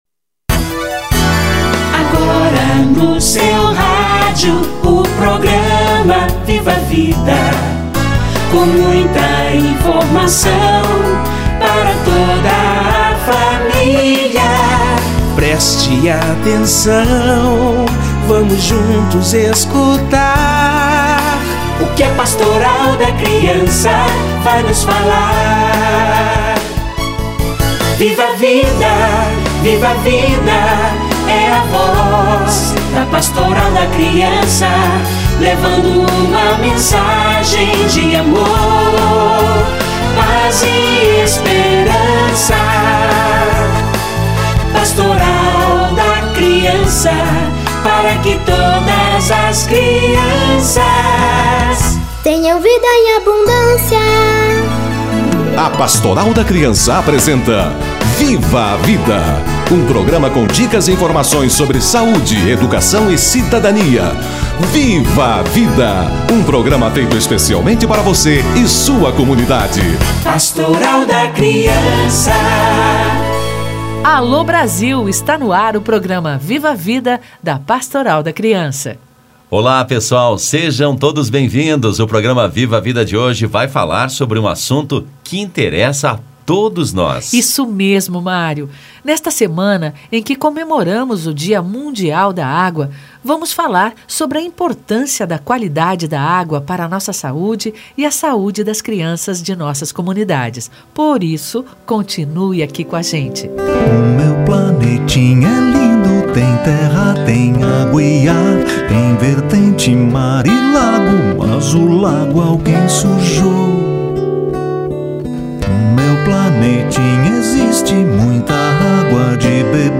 Dia Mundial da Água (OMS) - Entrevista